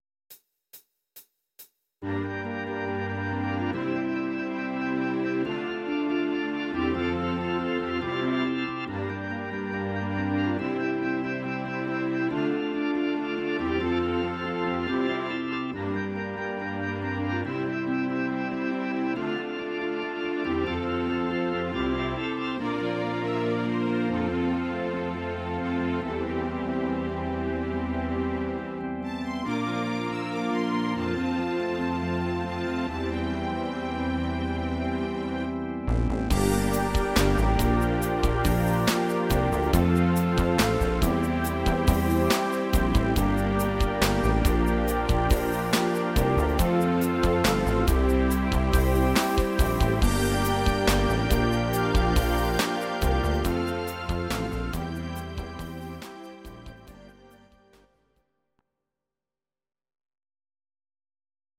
Genre: Todays hit charts
Key: C